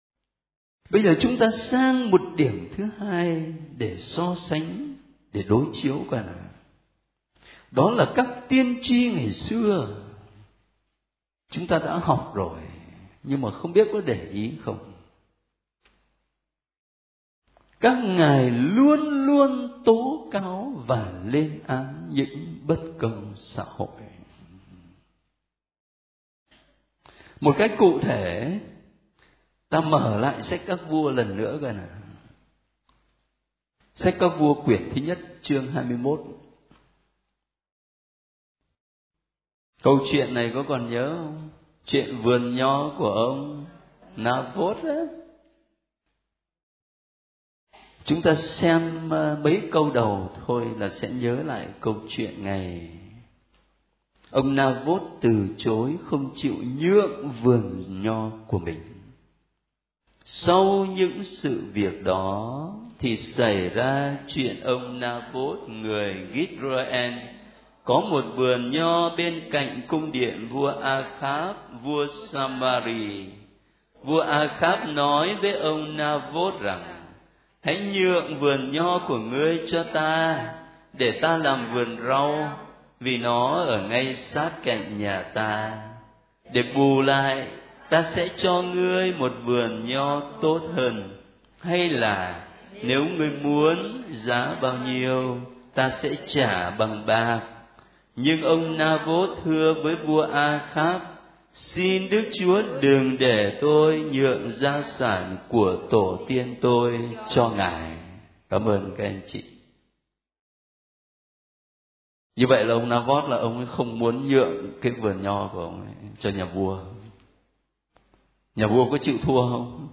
Các bài giảng về Thánh Kinh
Đức Cha phụ tá Phêrô Nguyễn văn Khảm